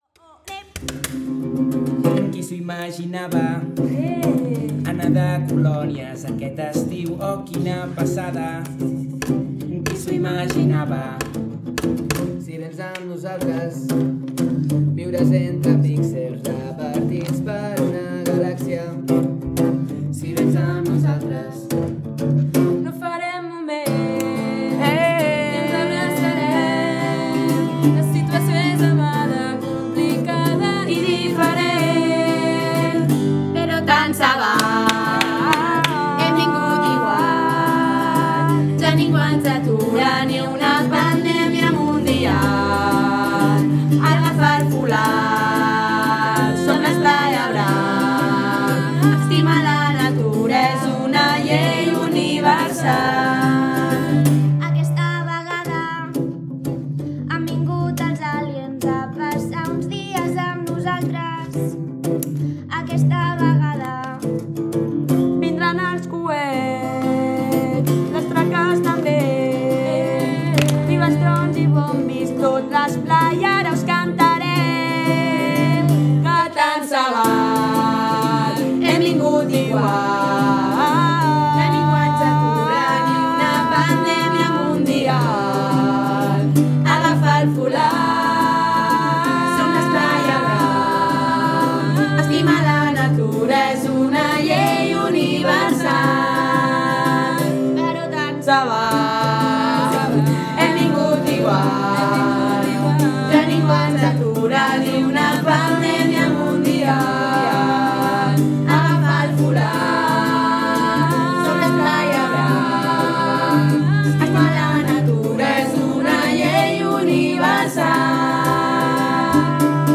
Can Solà 2020